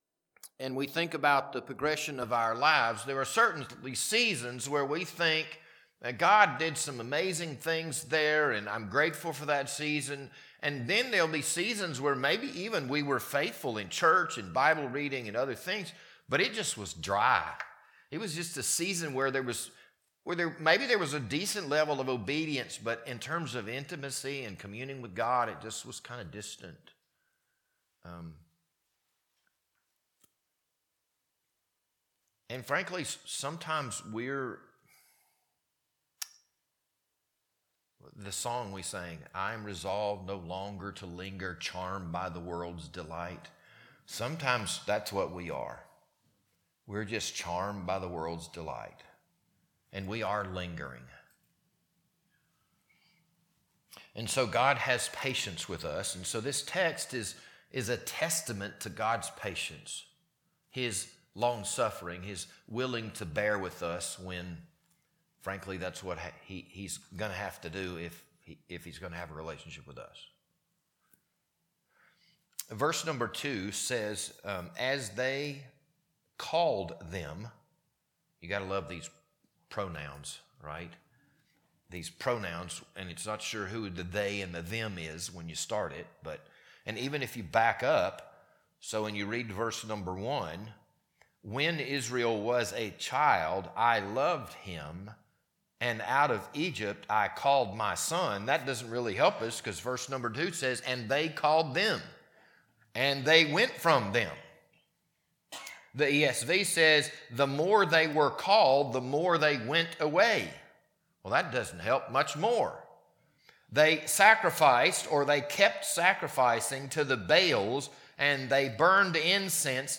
This Sunday evening sermon was recorded on January 11th, 2026.